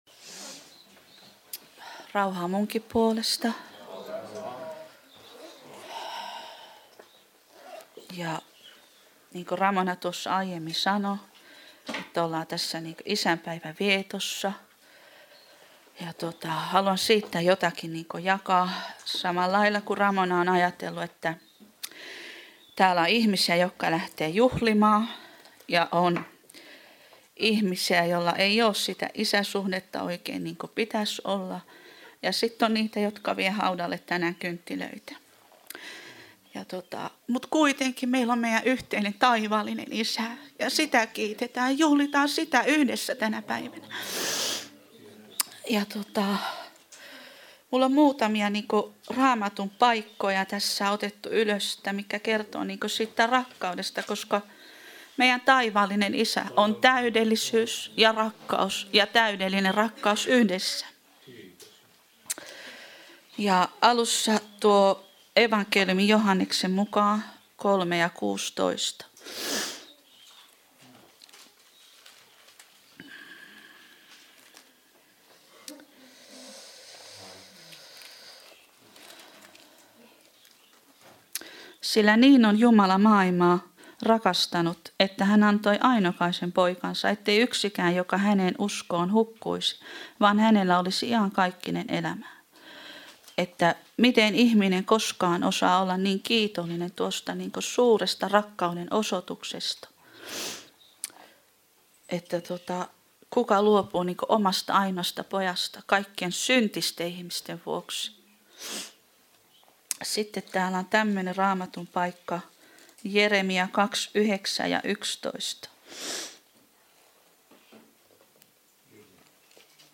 Service Type: Jumalanpalvelus